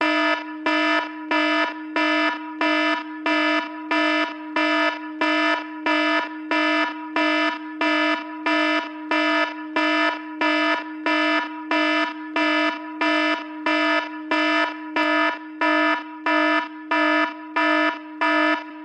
Alarm.mp3